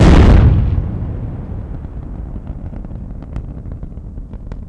Index of /90_sSampleCDs/AKAI S6000 CD-ROM - Volume 1/SOUND_EFFECT/EXPLOSIONS